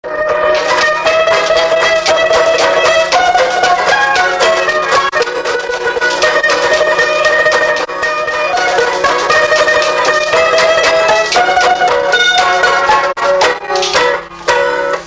Résumé Instrumental
Catégorie Pièce musicale inédite